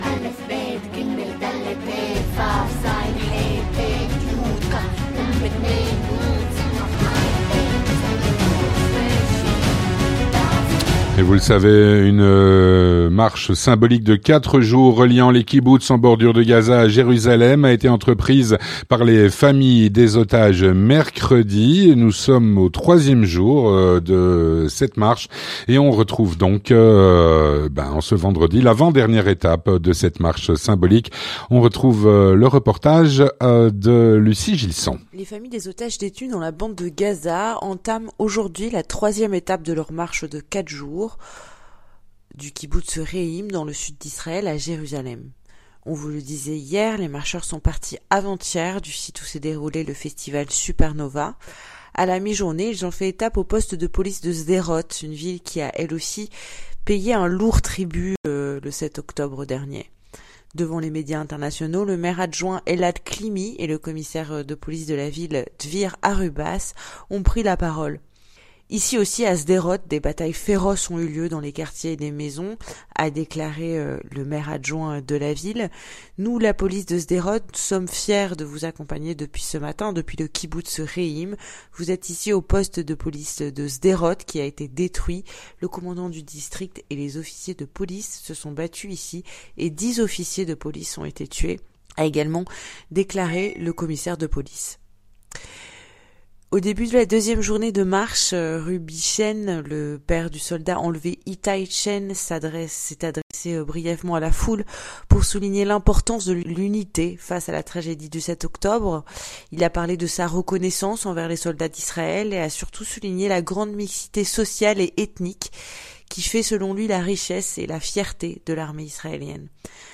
On vous propose un florilèges des prises de paroles qui ont eu lieu sur le chemin.
Un reportage